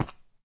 minecraft / sounds / step / stone3.ogg
stone3.ogg